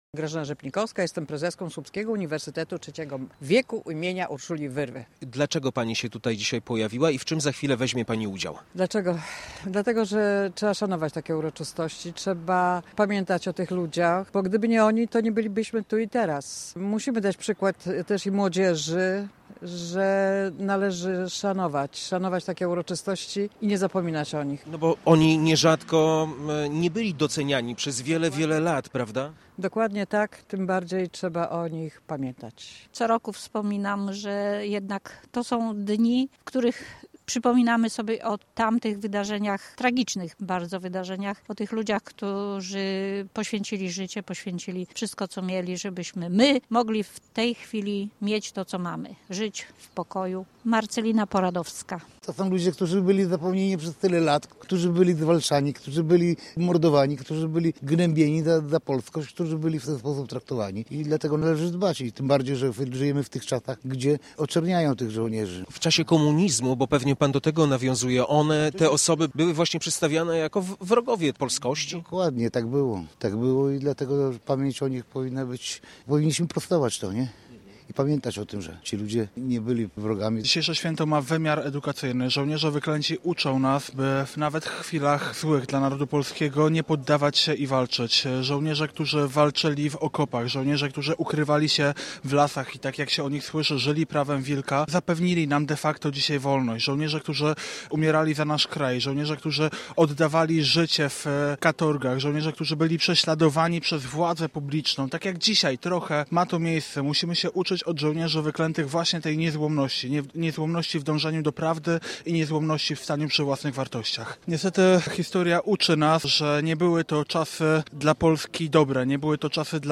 W rocznicę tego wydarzenia Słupszczanie oddali hołd bohaterowi, który jest patronem jednej z ulic miasta. W uroczystościach w Kościele św. Jacka wzięli udział przedstawiciele służb mundurowych oraz samorządowcy.